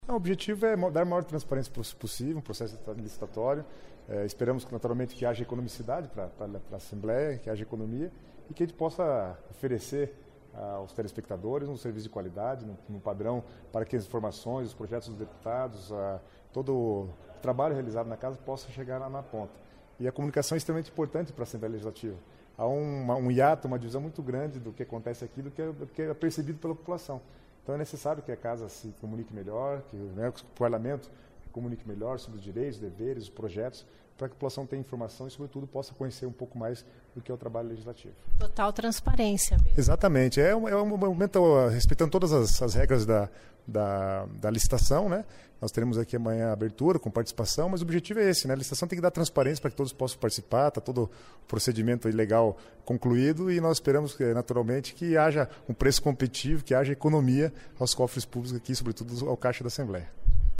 Ouça a entrevista com o deputado Guto Silva (PSD), que destacou, no começo da tarde desta quarta-feira (21) a transparência no processo de licitação para a escolha da produtora que vai transmitir o conteúdo da TV Assembleia.